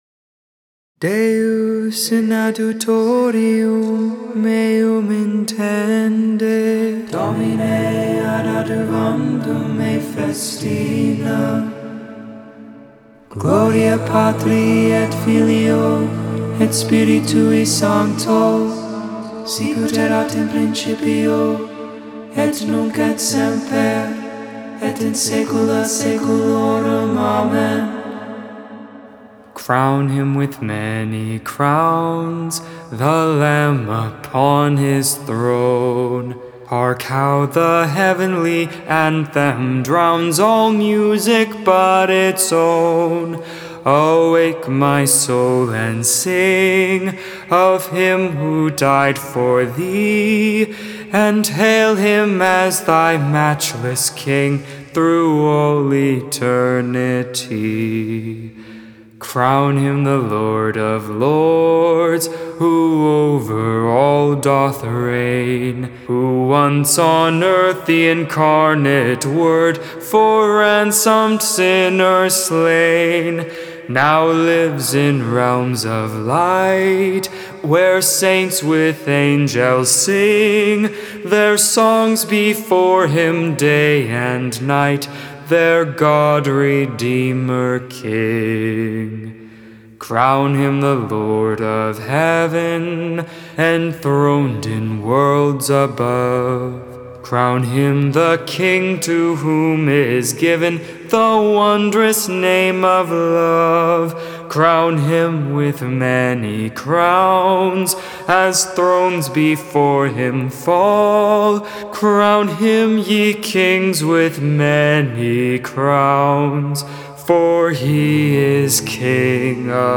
Vespers I, Saturday Evening Prayer for the 2nd Sunday in Lent.